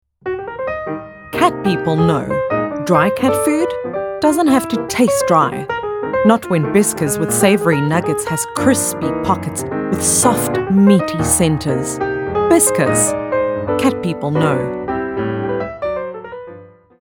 corporate, friendly